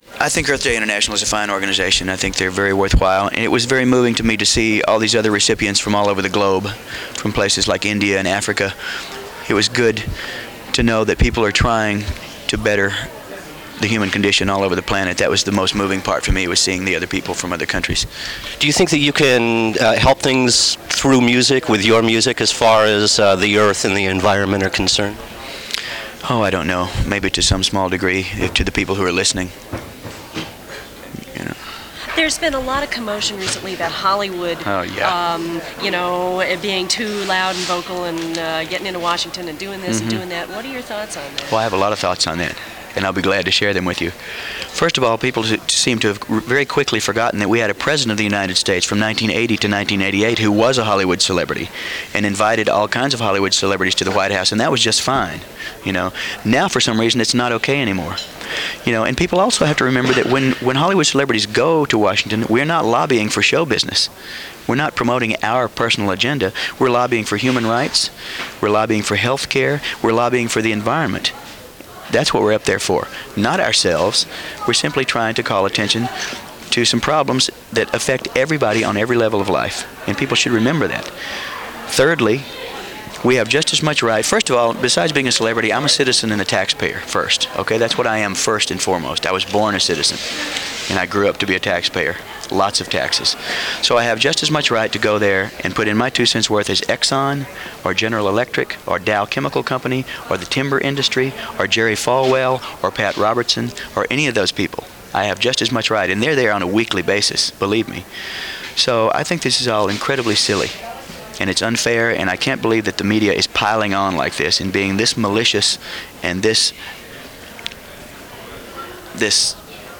Don Henley Press Conference – Winning a 1993 International Earth Award – June 10, 1993
This Press Conference, recorded shortly after the awards ceremonies, focuses on Henley’s then-recent purchase of the Walden Woods and founding the Walden Woods Project in 1990 for the preservation of Henry David Thoreau‘s legacy and protection of the lands from development.